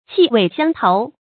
qì wèi xiāng tóu
气味相投发音
成语注音 ㄑㄧˋ ㄨㄟˋ ㄒㄧㄤ ㄊㄡˊ
成语正音 相，不能读作“xiànɡ”。